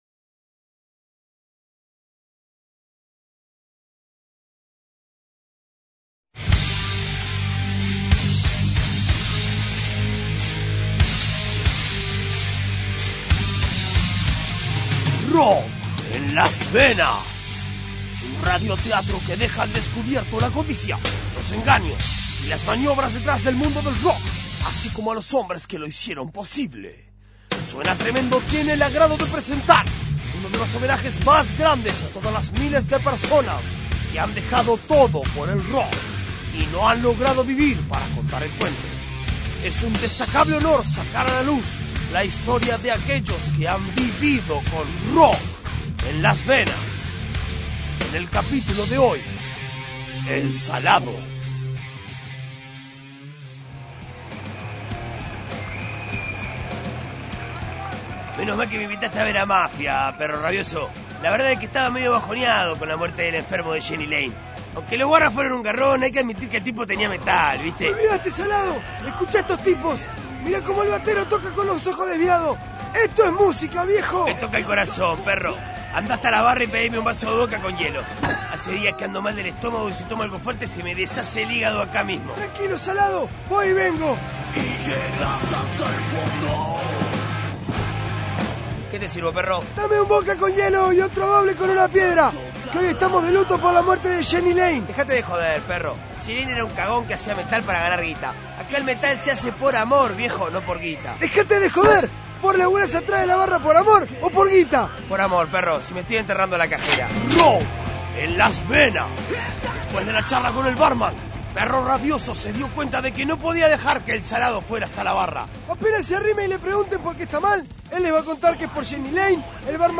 Un radioteatro que deja al descubierto la codicia, los engaños y las maniobras detrás del mundo del rock, así como a los hombres, que lo hicieron posible. En este capítulo "El Salado" y "Perro Rabioso" están de luto por la muerte del ex vocalista de Warrant, Jeni Lane.